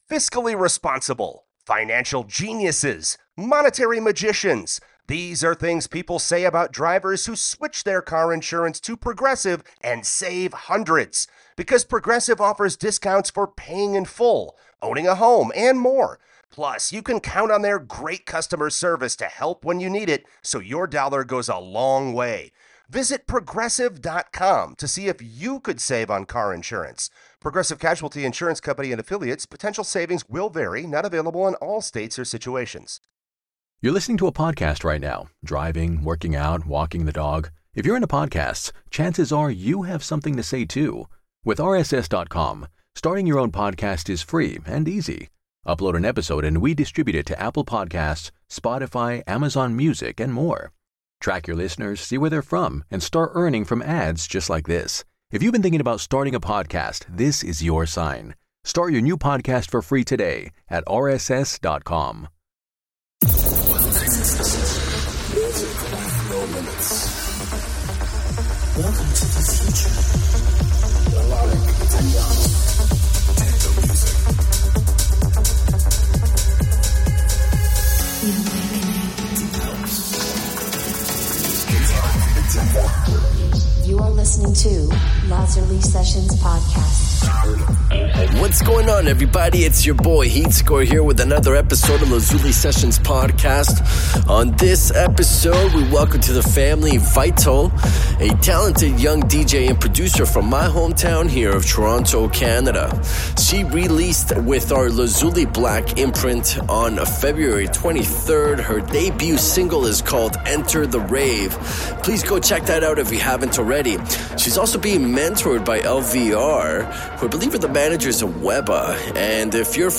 DJ and producer